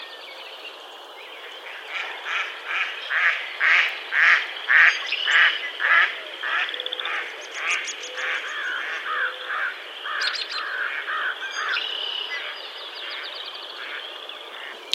Mallard
Females quack loudly. Males have a low call that sounds like kewk-kewk.
mallard-call.mp3